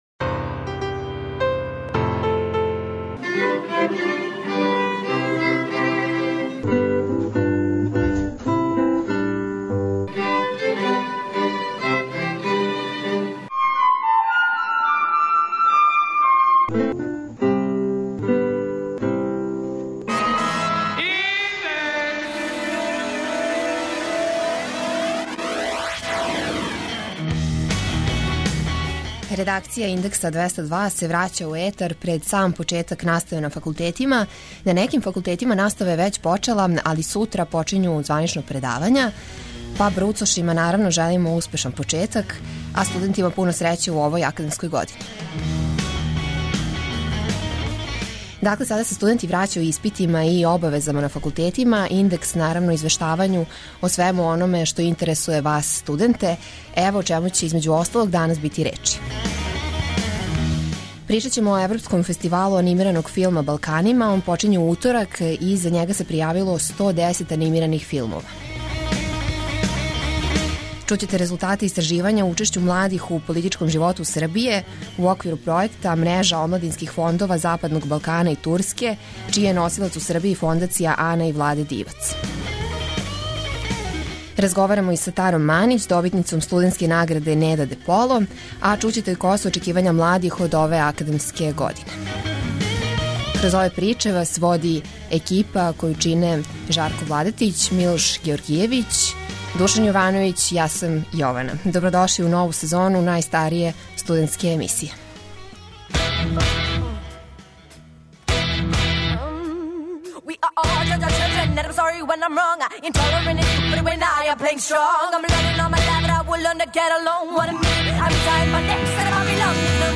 Добродошли у нову сезону најстарије студентске радио-емисије.